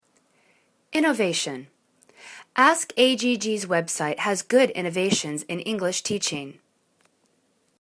innovation  /inә'va:shәn/ n